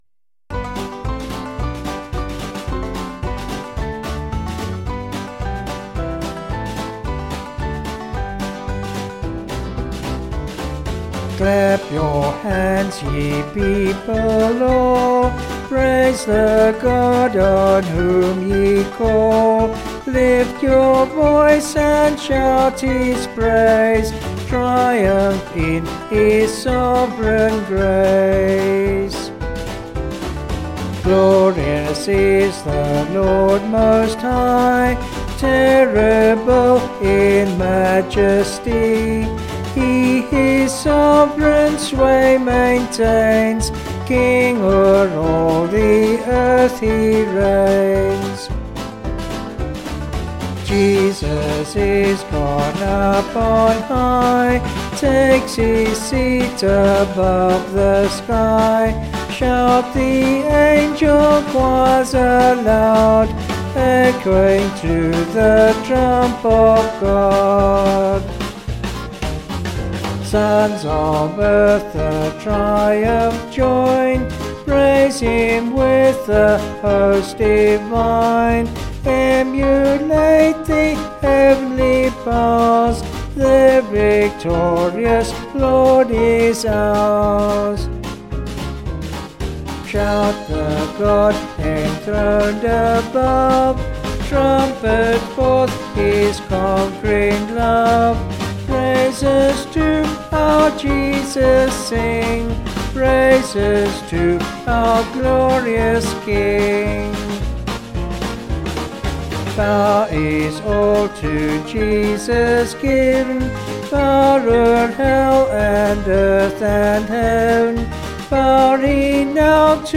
Vocals and Band   264.9kb